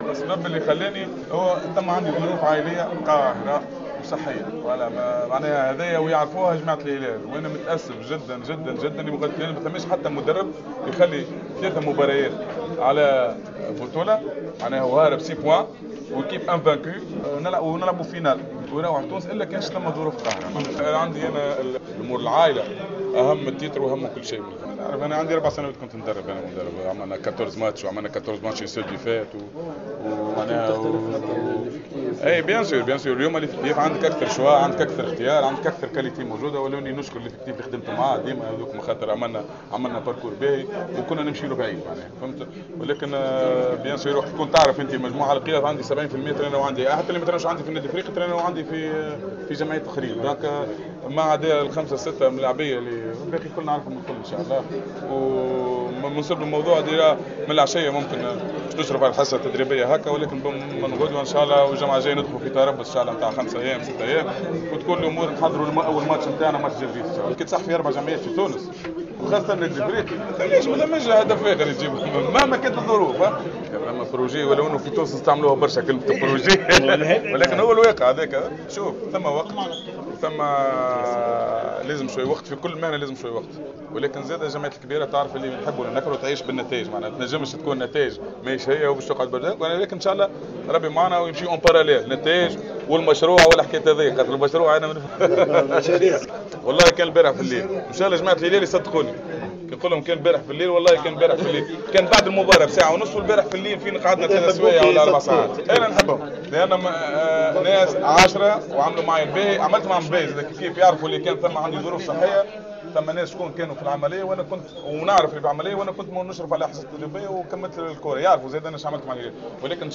أكد المدرب الجديد للنادي الإفريقي نبيل الكوكي خلال الندوة الصحفية التي عقدها اليوم أن أسبابا عائلية وراء عودته لتونس بعد قضى فترة في تدريب الهلال السوداني معربا عن سعادته بإشرافه على فريقه الأم و أشار أن من ضمن أهدافه اللعب من أجل جميع الألقاب المتاحة خاصة دوري أبطال إفريقيا التي سيشارك فيها الإفريقي إضافة إلى المحافظة على لقب البطولة التونسية.